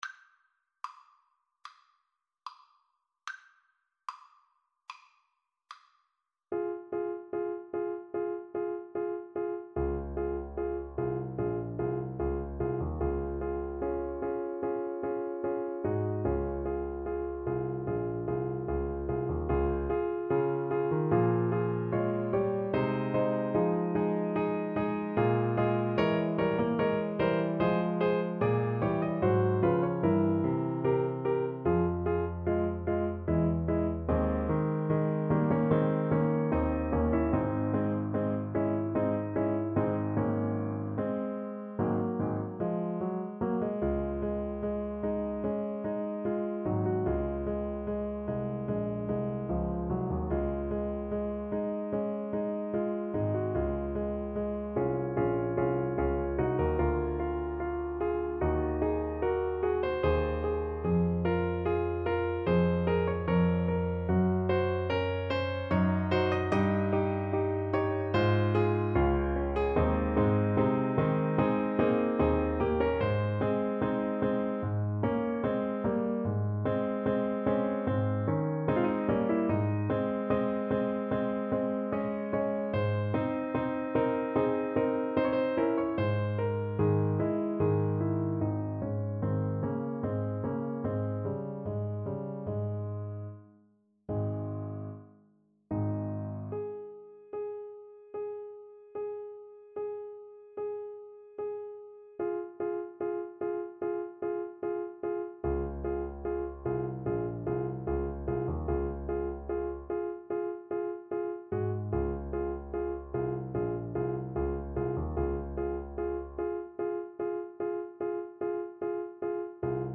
~ = 74 Moderato
4/4 (View more 4/4 Music)
Classical (View more Classical Saxophone Music)